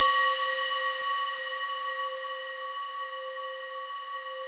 RESMET C5 -L.wav